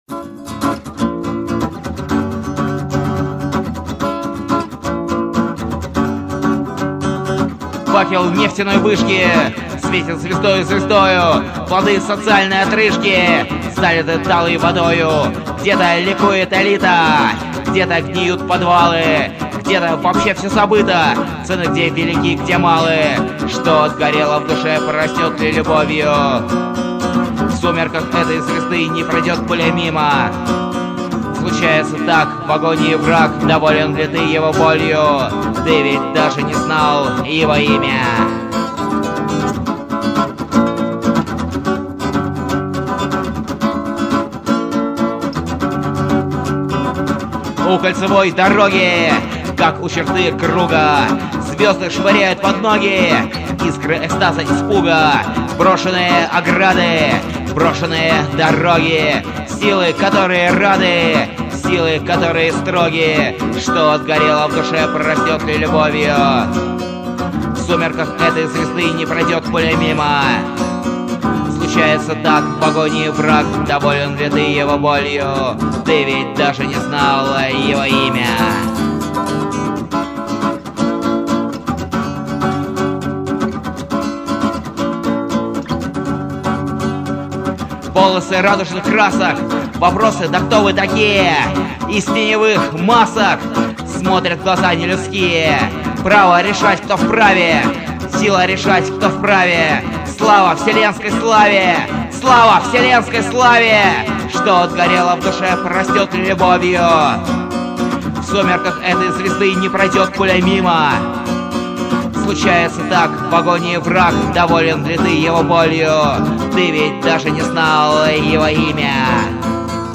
вокал
гитара
Коптевская звукозаписывающая студия, 12 июля 2005 года.